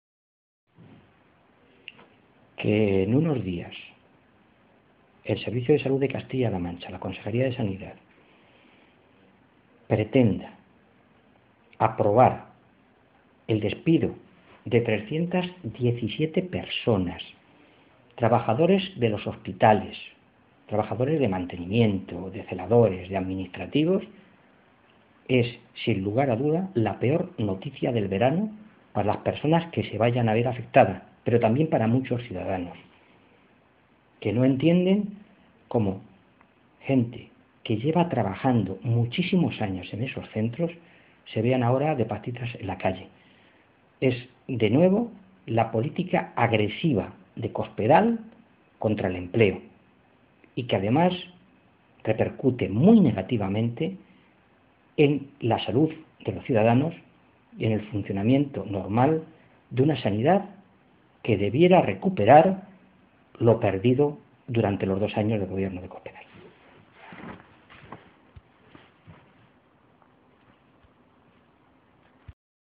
Fernando Mora, portavoz de Sanidad del Grupo Socialista
Cortes de audio de la rueda de prensa